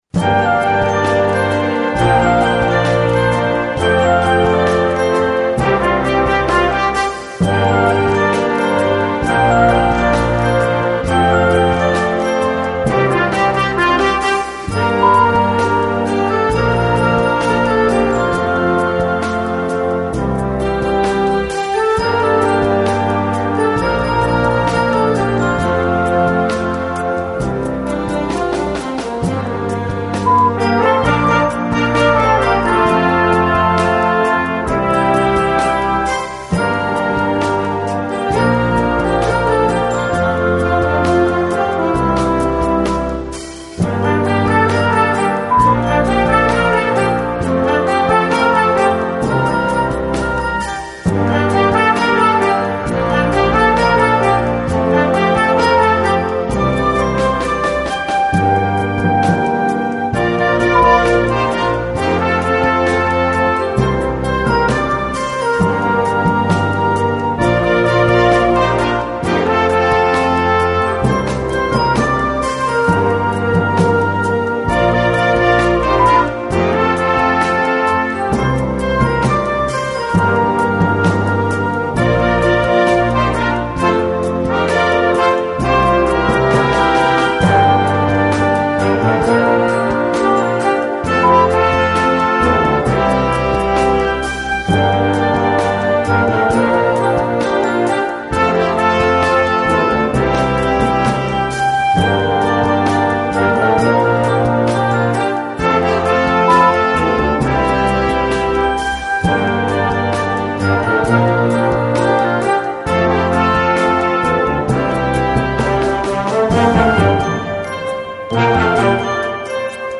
2 Besetzung: Blasorchester Tonprobe